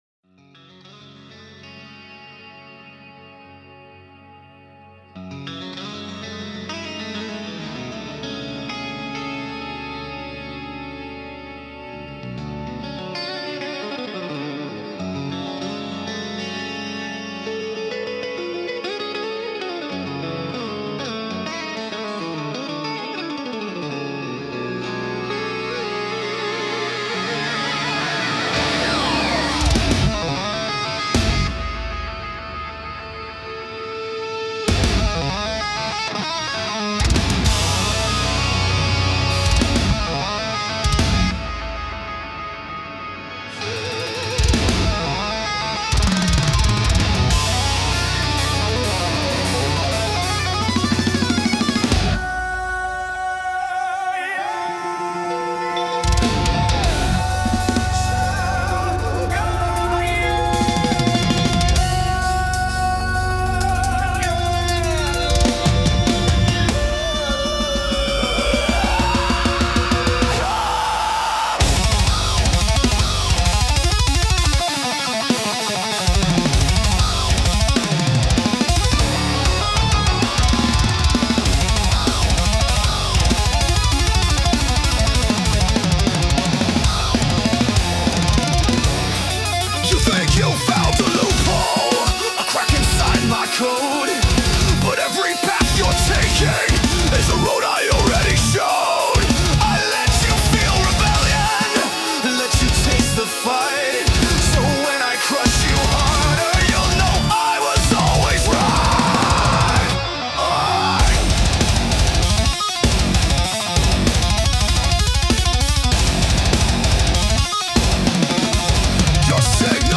math metal singer
bass guitar
synth & fx